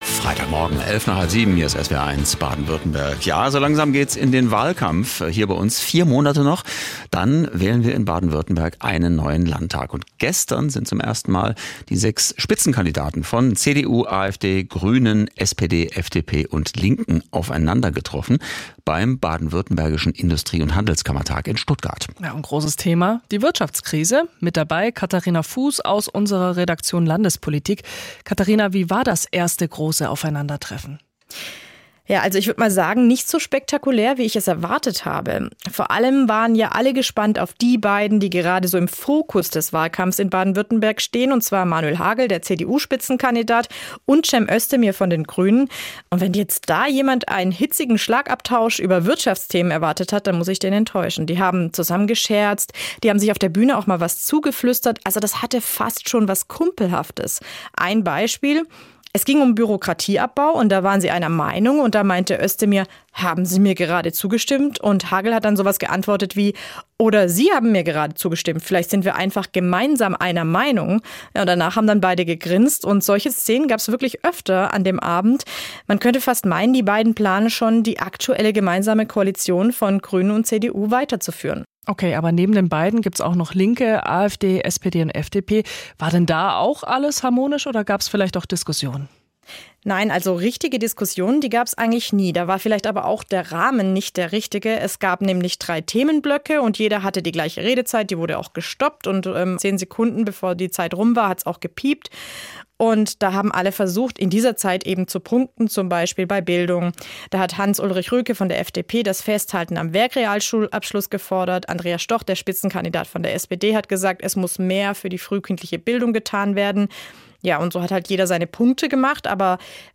war bei der Podiumsdiskussion in Stuttgart vor Ort: